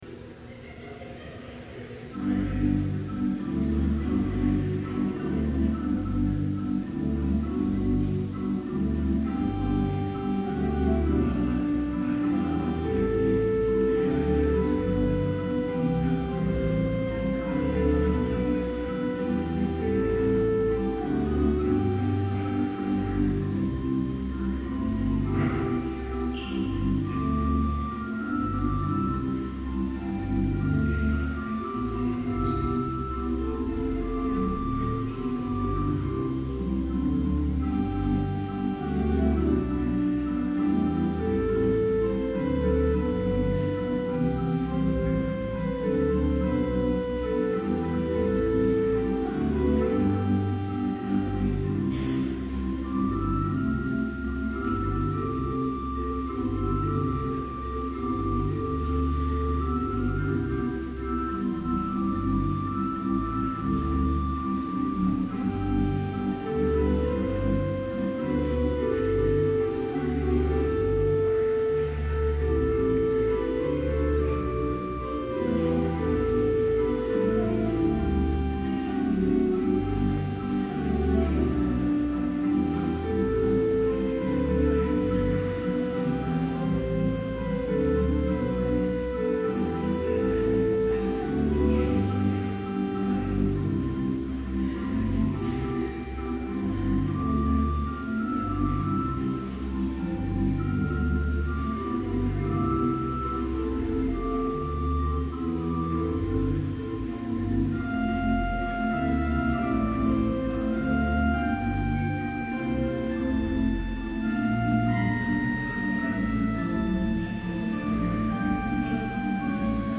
Complete service audio for Summer Chapel - June 22, 2011